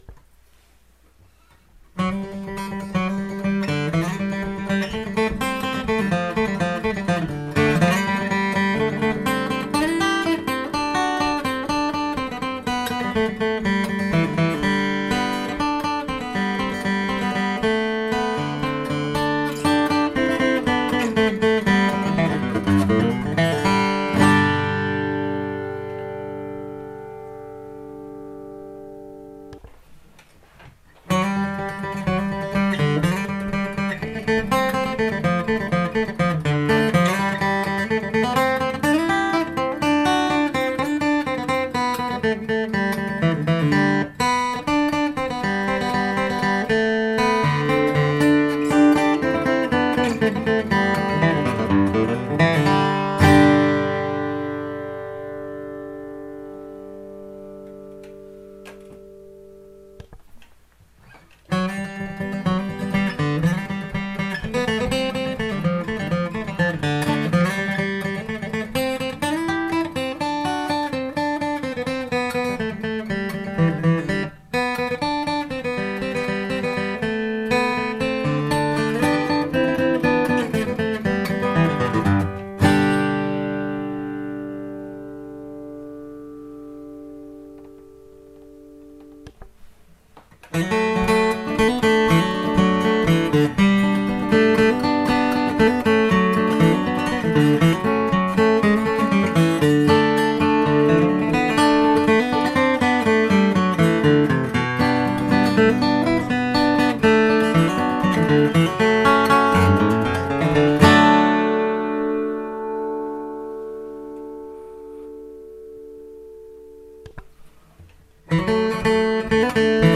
В этом коротком ролике два Гревенса и мой HD28V каждый выполняют G, а затем каждый капо в 2 и использует C-образную форму.